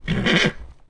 咴咴